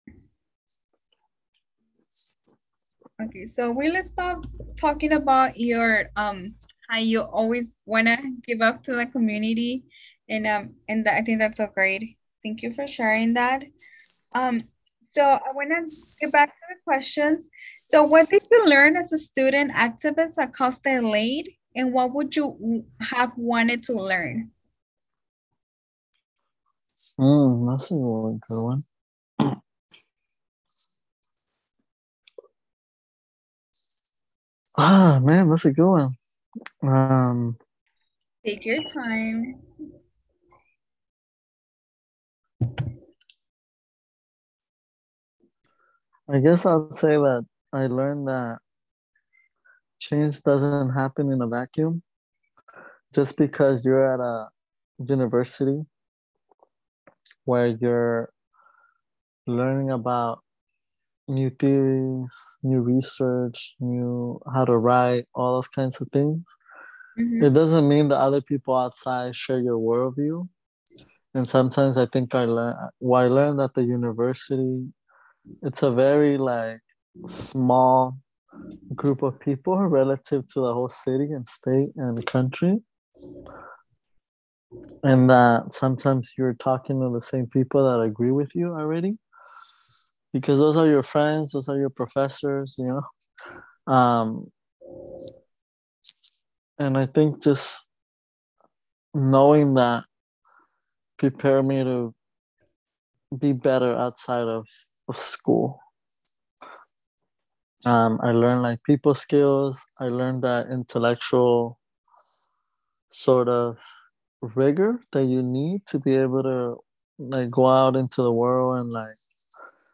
Interview
A thirty-two minute long audio interview